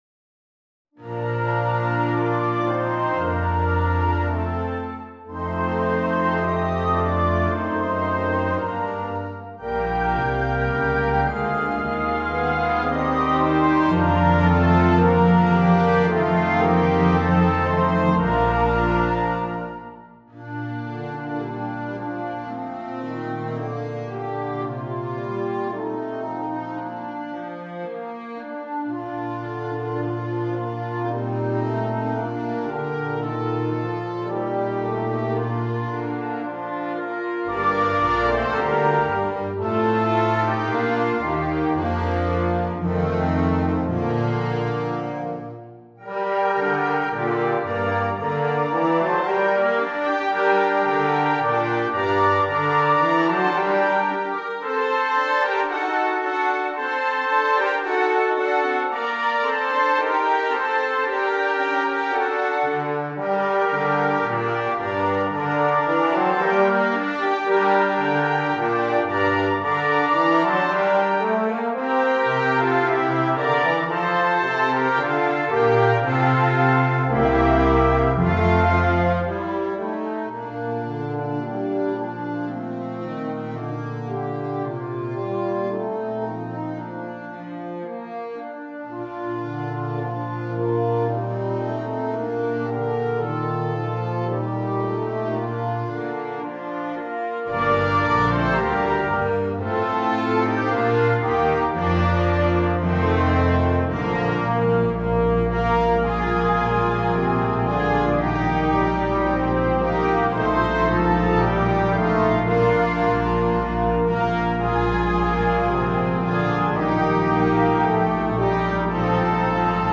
Messe für Blasorchester und Chor (opt.)
Besetzung: Blasorchester